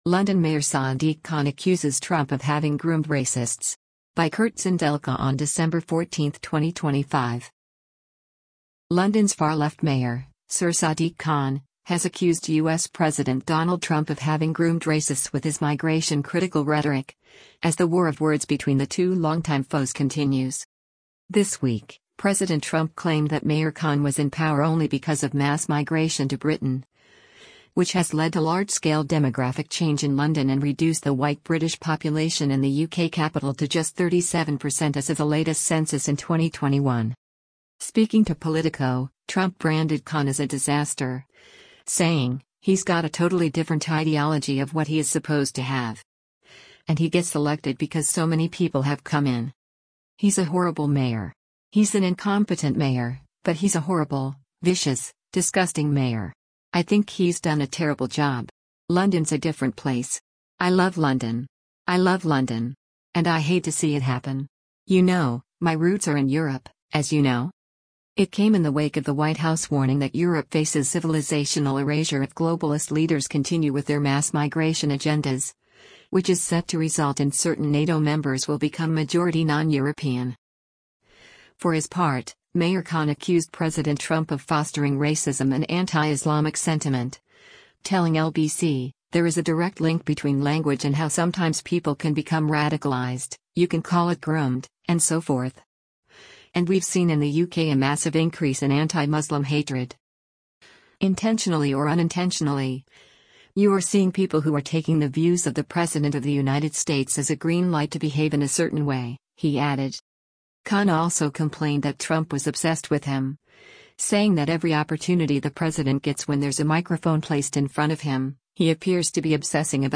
Mayor of London Sir Sadiq Khan during a phone-in on LBC with James O'Brien, at the Global